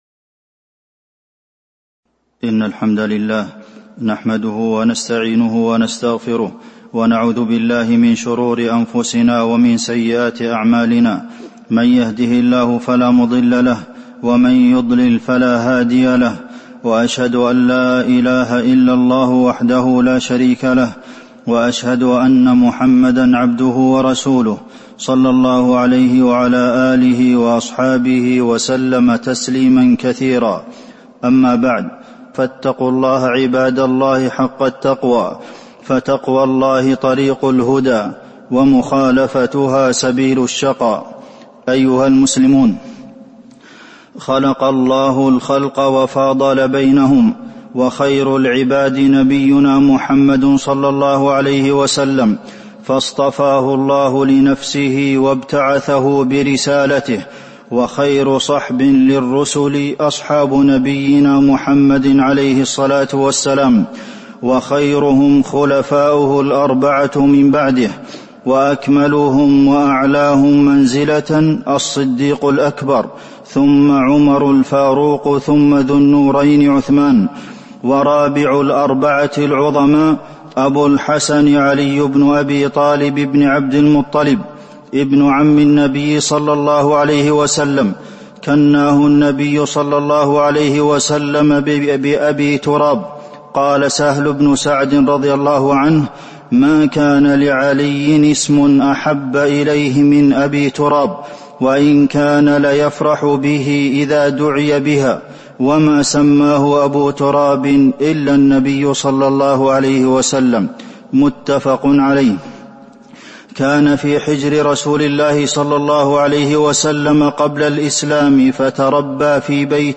تاريخ النشر ٢٢ ذو القعدة ١٤٤٢ هـ المكان: المسجد النبوي الشيخ: فضيلة الشيخ د. عبدالمحسن بن محمد القاسم فضيلة الشيخ د. عبدالمحسن بن محمد القاسم علي بن أبي طالب